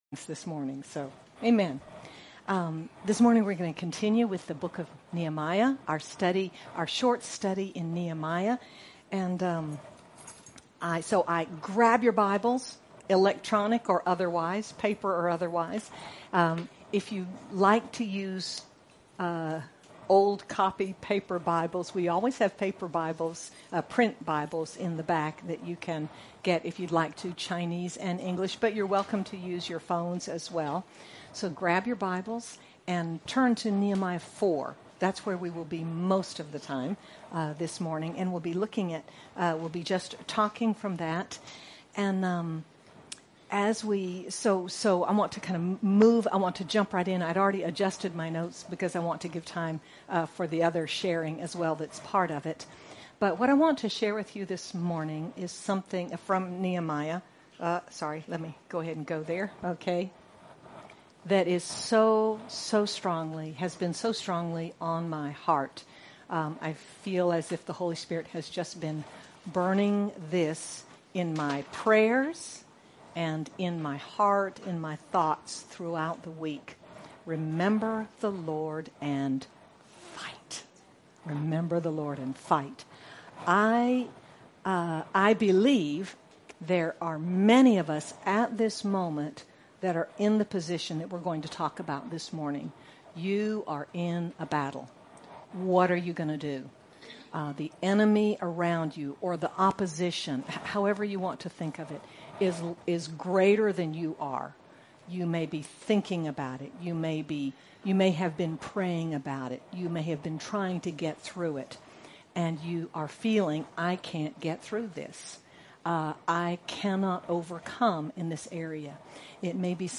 Nehemiah gives us a masterclass in how to overcome in spiritual battles. Sermon by